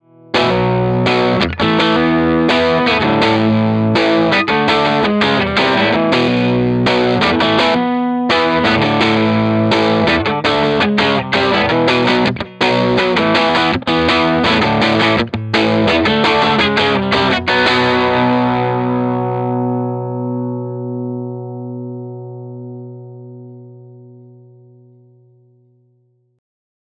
18 Watt v6 - 6V6 Dirty Tone Tubby Ceramic
Note: We recorded dirty 18W tones using both the EL84 and 6V6 output tubes.
Keep in mind when listening that the tones I dialed in were "average" tones.
18W_DIRTY_6V6_ToneTubbyCeramic.mp3